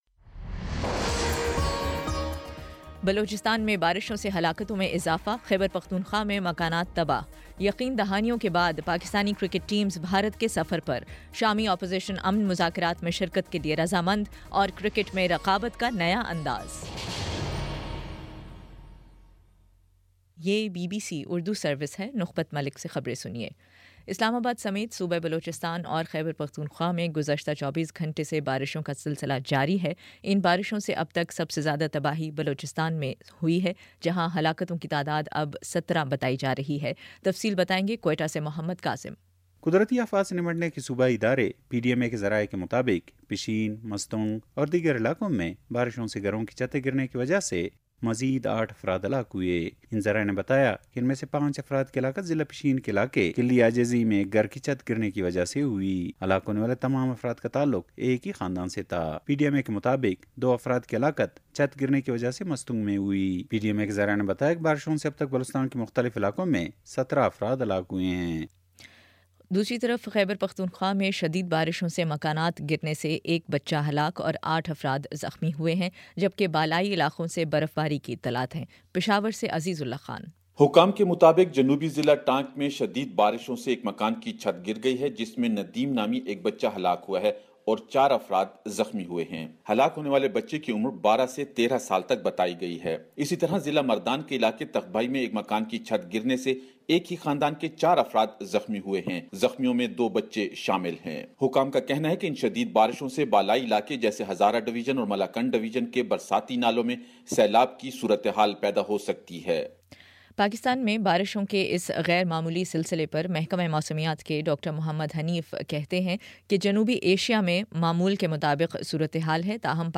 مارچ 12 : شام پانچ بجے کا نیوز بُلیٹن